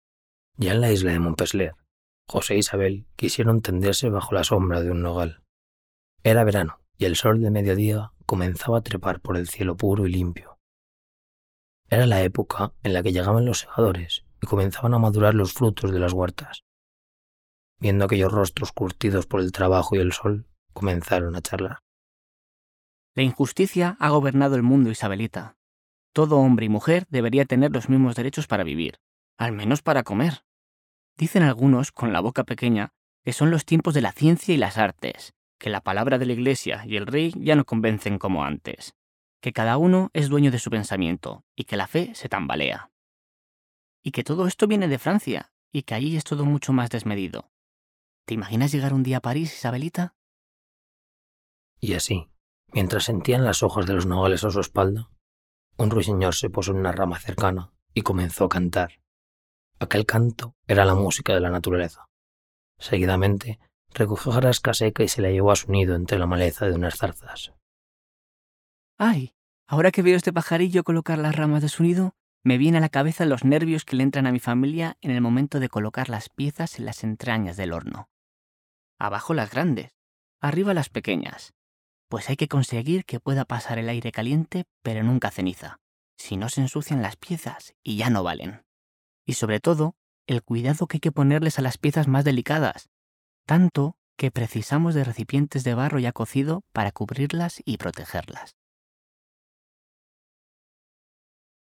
Audioguía Júcar, vida y memoria de una ciudad